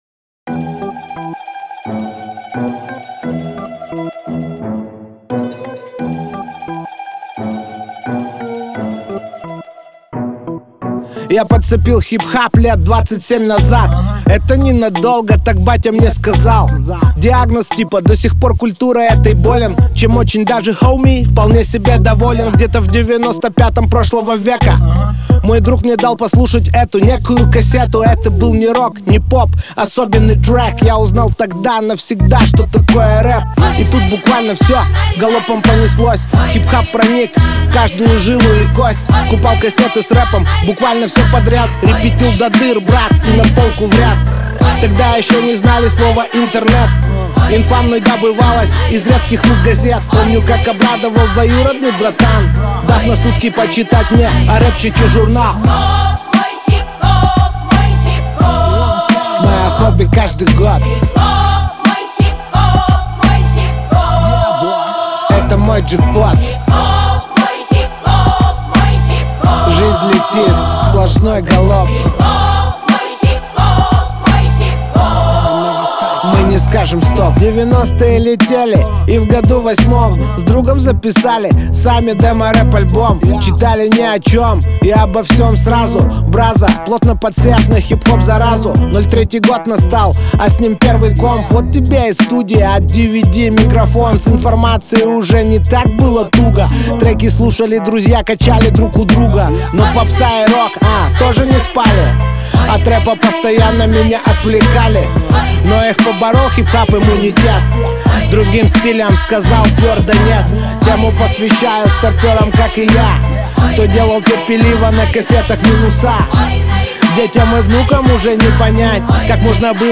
Жанр: Pop | Год: 2026